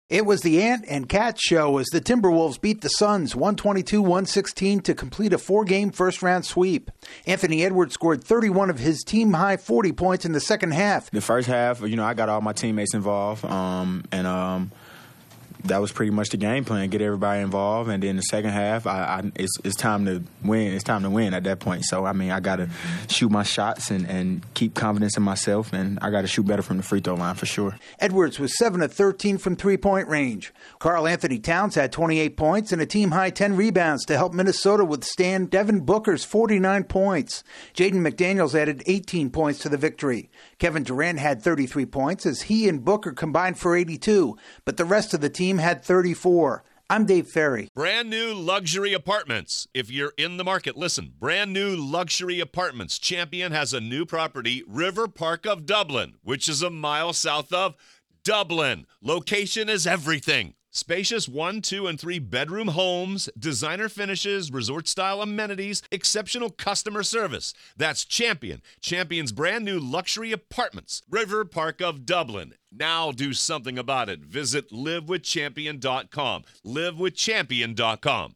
The Timberwolves' two best players lead them into the second round. AP correspondent